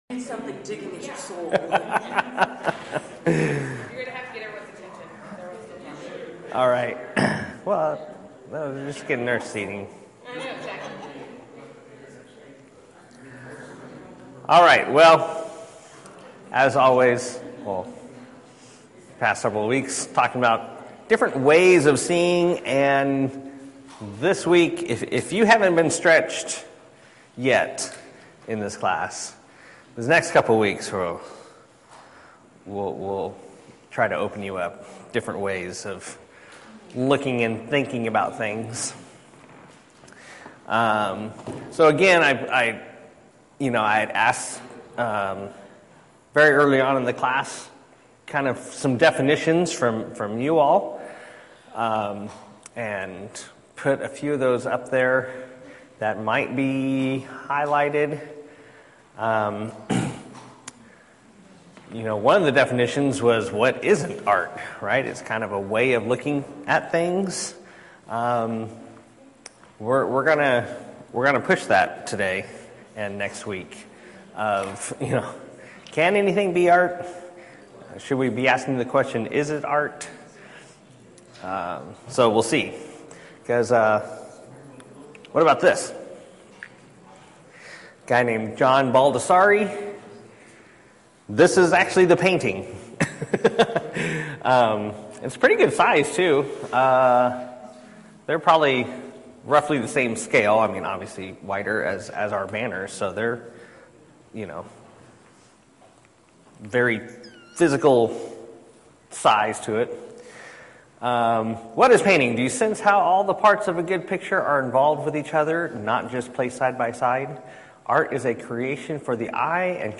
Service Type: 9:30 Hour - Class